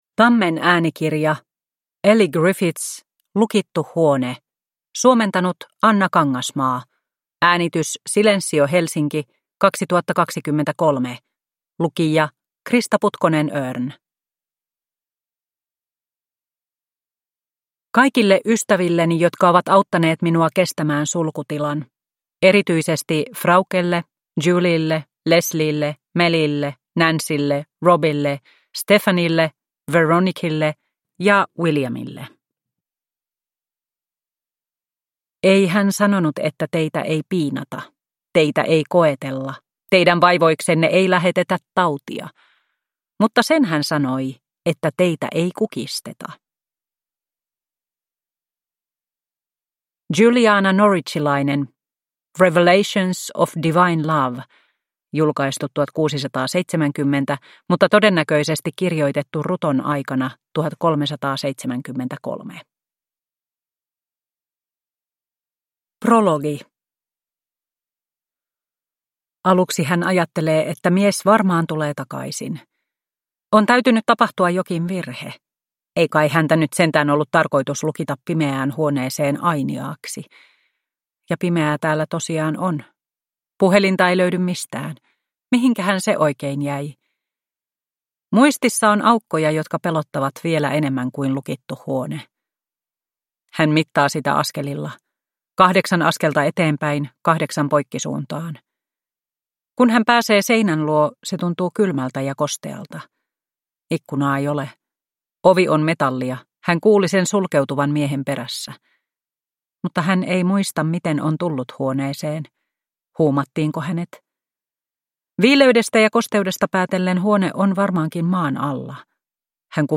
Lukittu huone – Ljudbok – Laddas ner